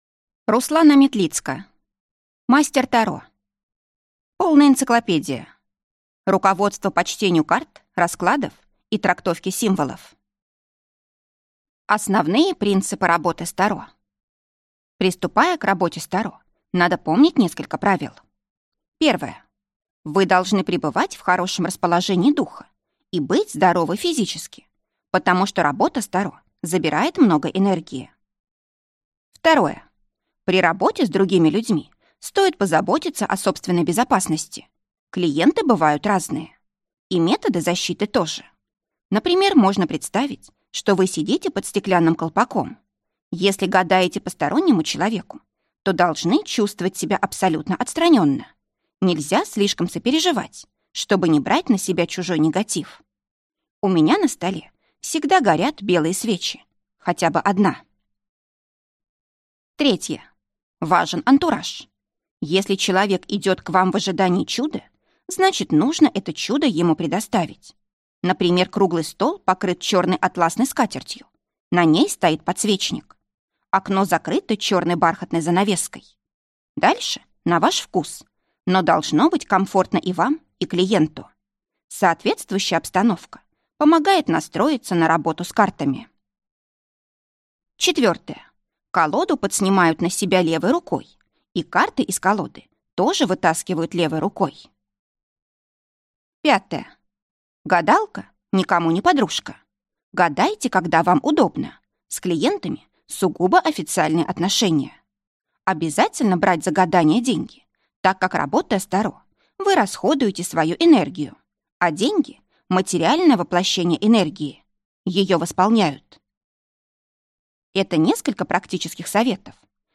Аудиокнига Мастер Таро. Полная энциклопедия. Руководство по чтению карт, раскладов и трактовке символов | Библиотека аудиокниг